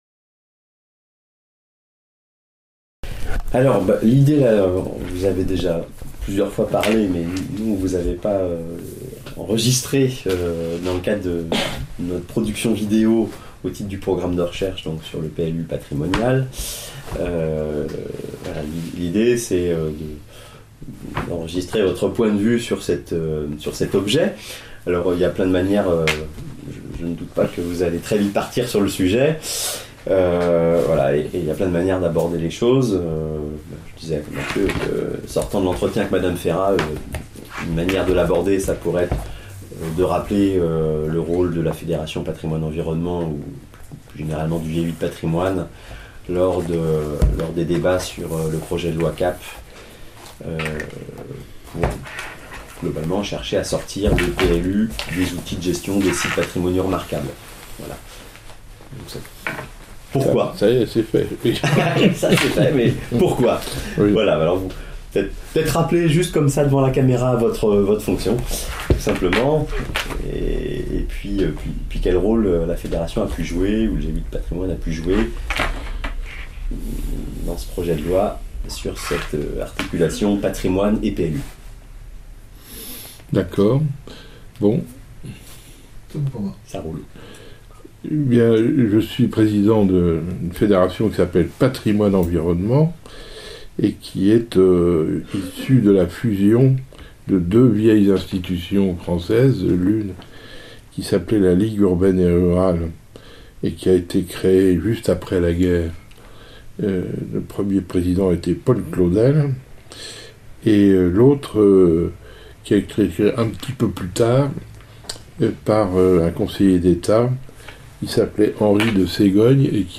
Entretien réalisé à Paris le 9 mai 2017. À noter : Cet entretien est un matériau de recherche brut, qui a nourri les réflexions des chercheurs dans le cadre du projet PLU PATRIMONIAL.